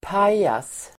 Ladda ner uttalet
Uttal: [p'aj:as]